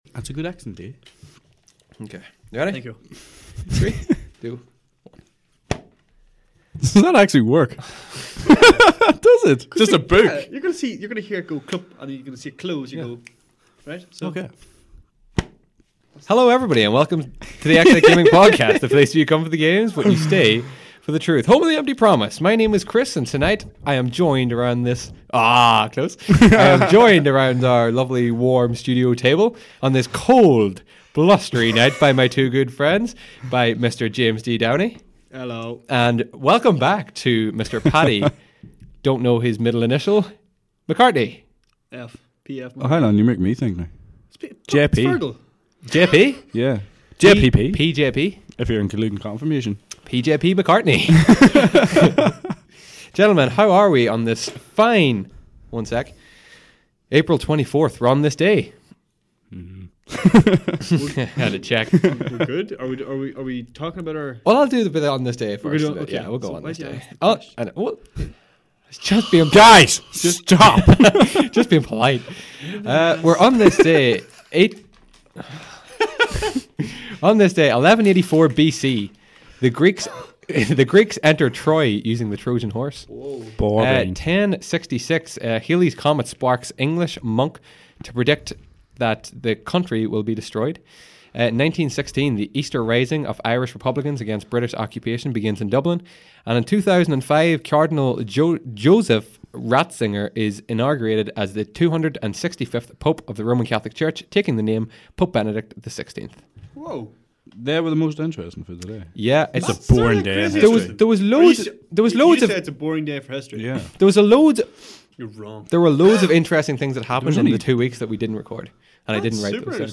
WARNING: The following podcast is an UNEDITED version, apart from the throw on intro and outro…This is RAW!